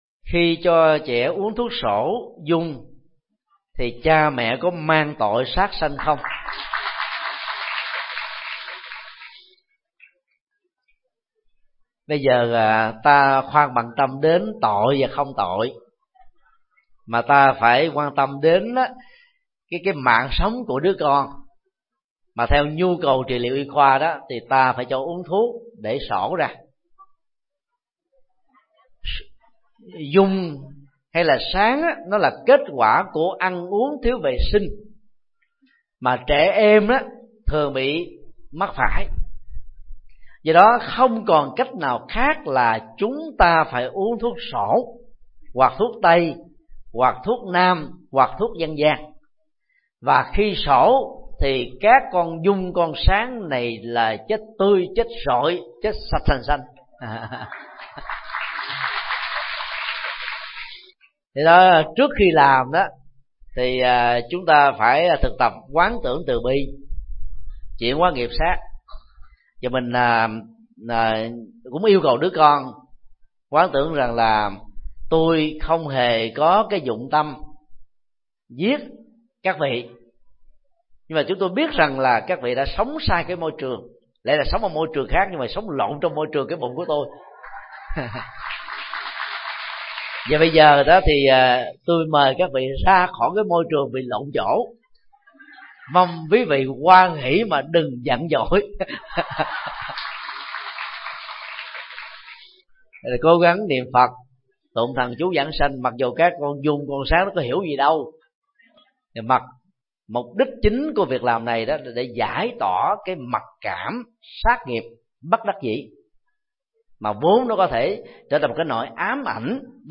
Vấn đáp: Xổ giun có gây nghiệp sát – Thích Nhật Từ